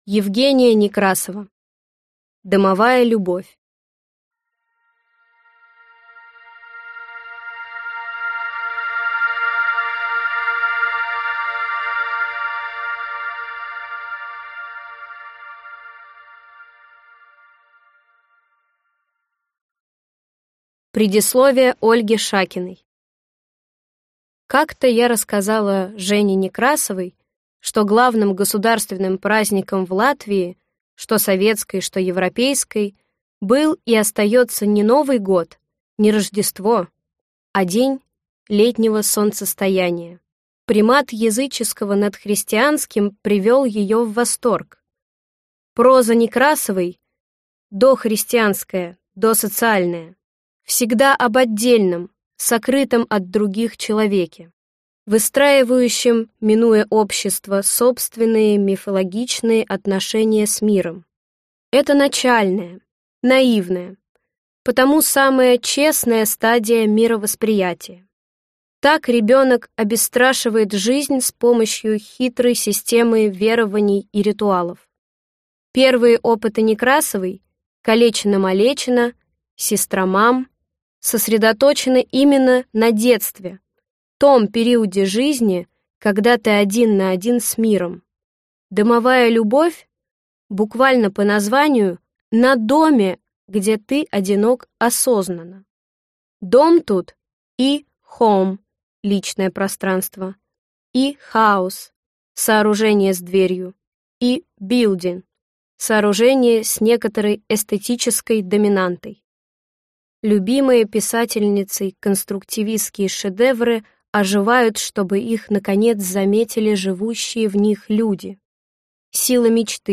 Аудиокнига Домовая любовь | Библиотека аудиокниг